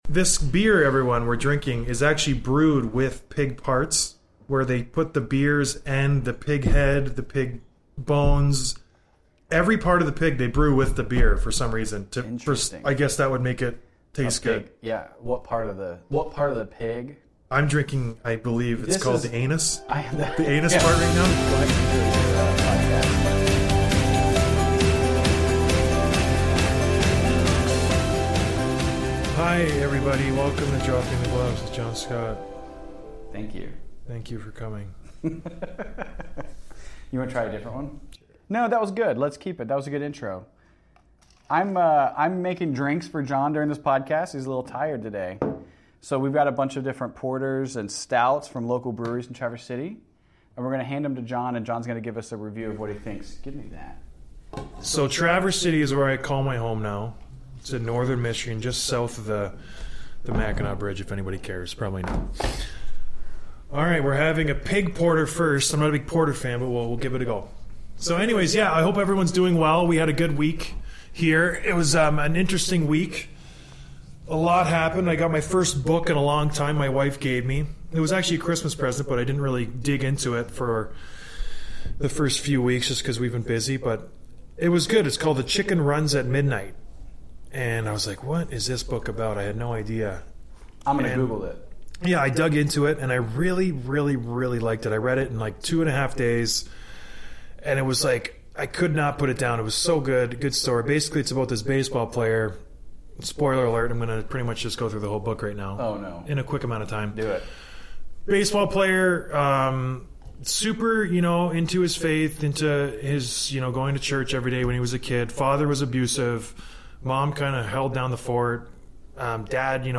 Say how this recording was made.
So, I am with my buddies at Web Canopy Studio doing a winter beer tasting.